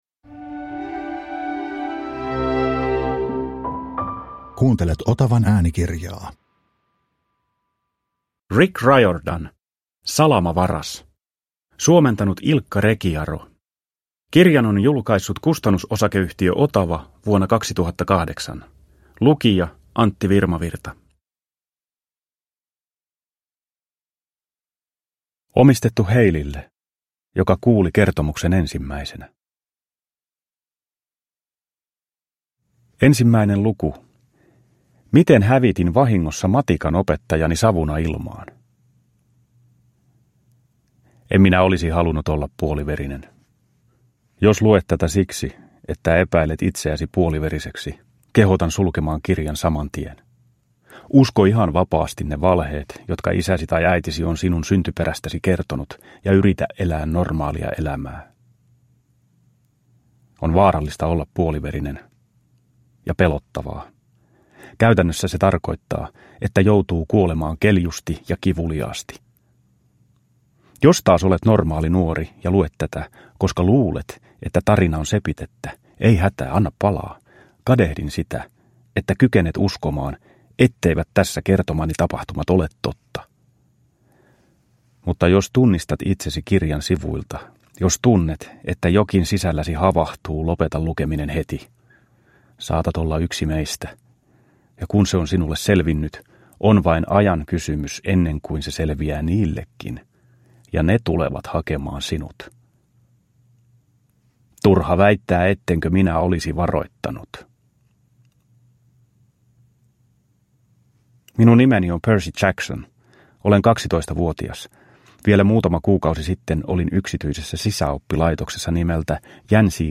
Salamavaras – Ljudbok – Laddas ner
Uppläsare: Antti Virmavirta